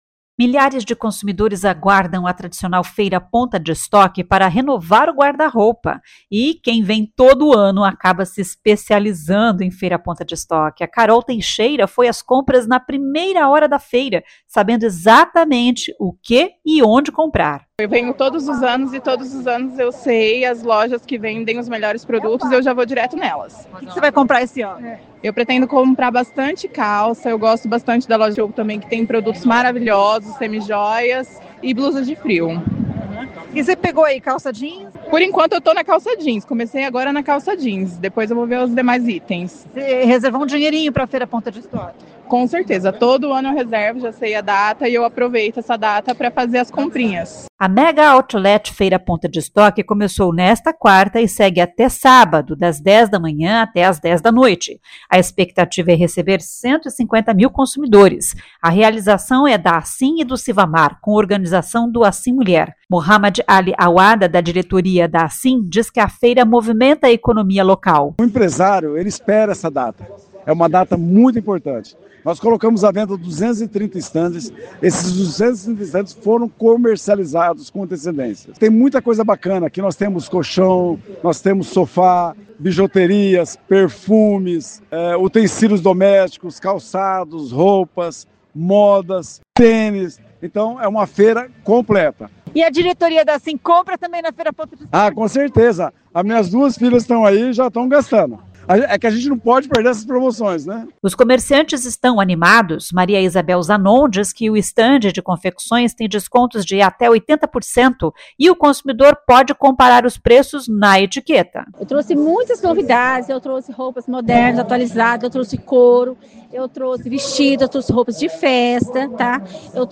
Os comerciantes estão animados.